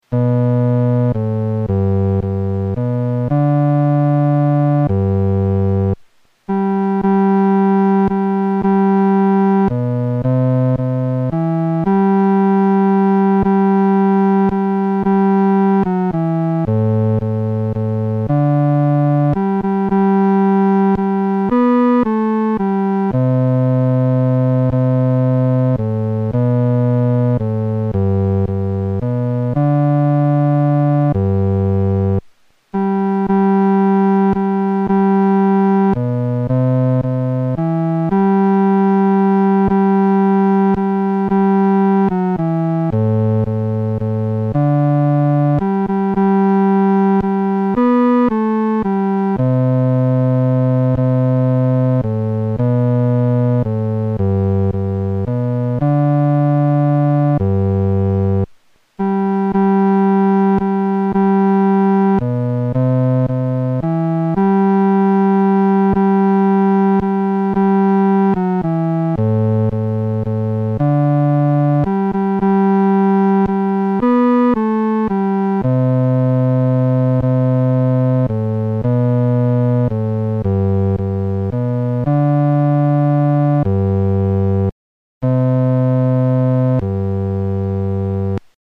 伴奏
男低
指挥在带领诗班时，表情和速度应采用温柔而缓慢地。